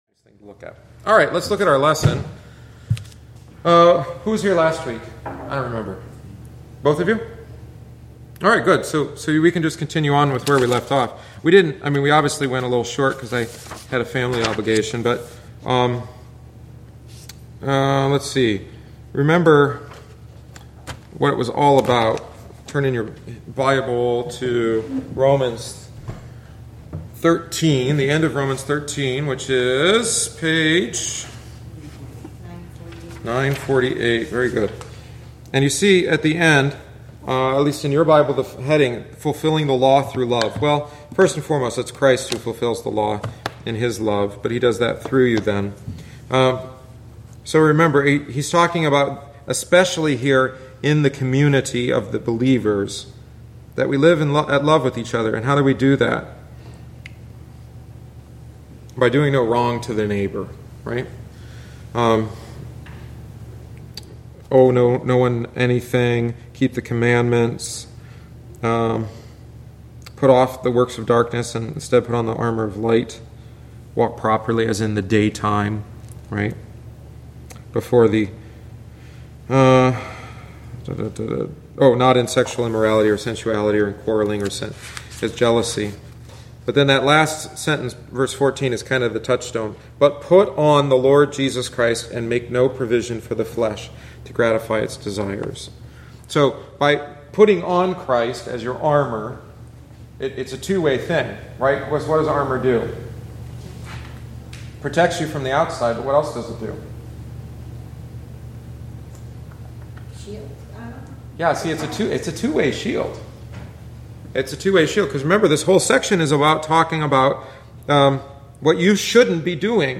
The following is the thirty-third week’s lesson. Weak and strong Christians should live together in love. Both are serving God in their own way.